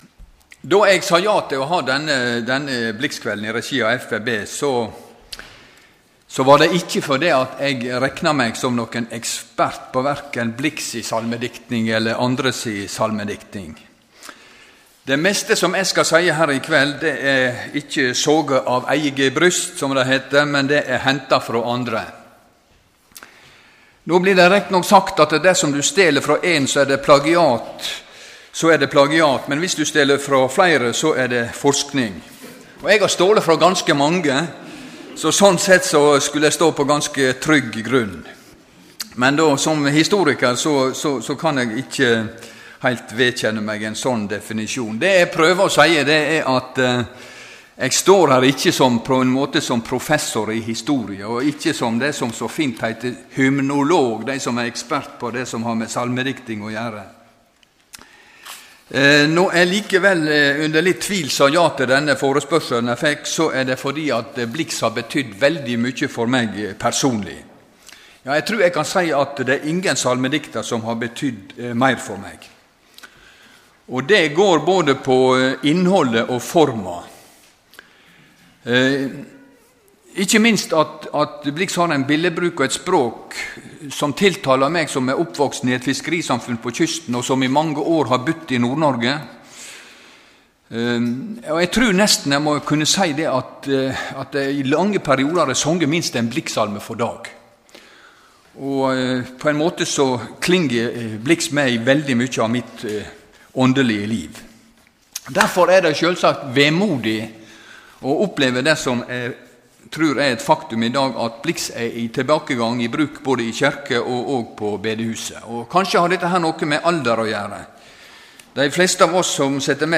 Dette foredraget ble holdt 14.03.2013 i regi av FBB-Bjørgvin i DELK-kirken.